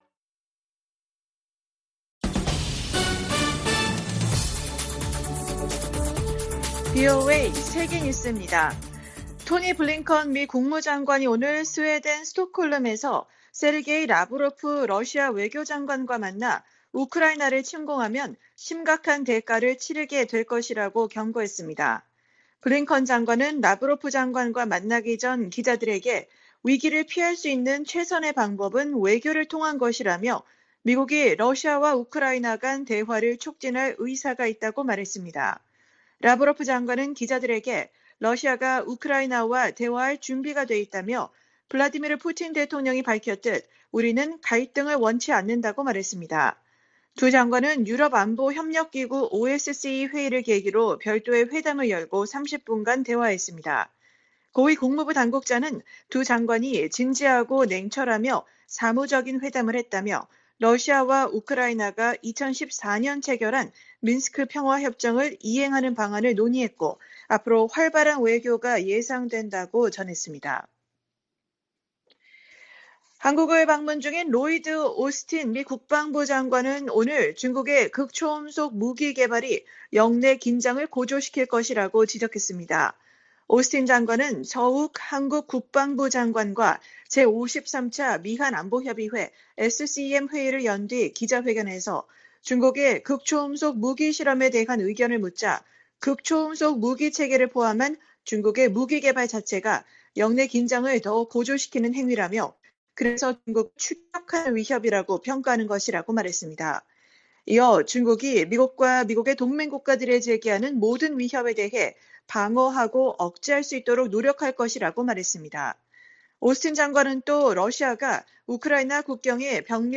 VOA 한국어 간판 뉴스 프로그램 '뉴스 투데이', 2021년 12월 2일 3부 방송입니다. 미-한 두 나라 국방 장관은 서울에서 양국 안보협의회를 마치고 북한의 핵과 미사일 능력에 대응하는 새로운 작전 계획 수립을 위해 새로운 기획지침을 승인했다고 밝혔습니다. 미국민 78%가 북한을 적국으로 인식한다는 여론조사 결과가 나왔습니다.